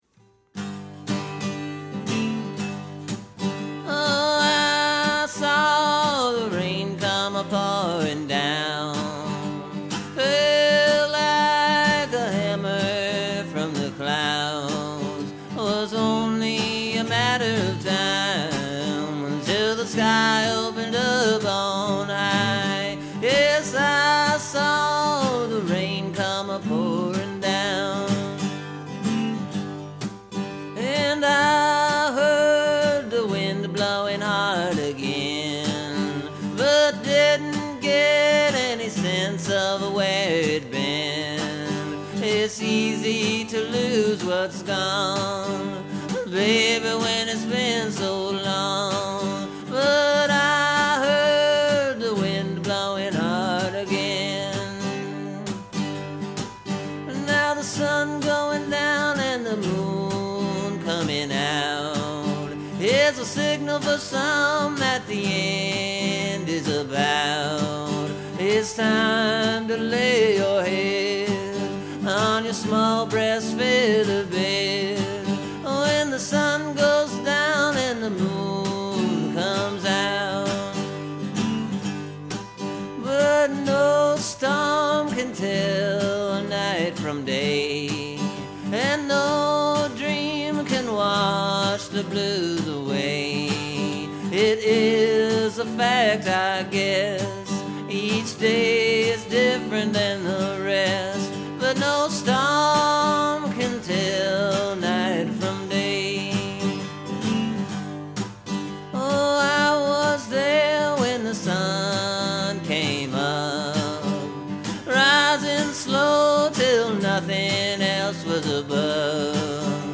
New Song: Blues Ballad in G major
Anyway, I wrote this one on Monday night. It’s basically done, except for maybe a mouth harp break or two, which I didn’t put in this recording because I got the hay fever bad these days.